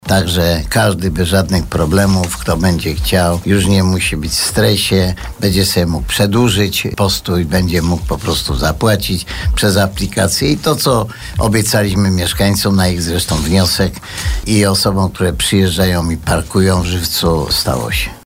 Mówi burmistrz miasta, Antoni Szlagor.